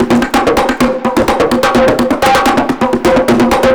Index of /musicradar/analogue-circuit-samples/128bpm/Drums n Perc
AC_PercB_128-04.wav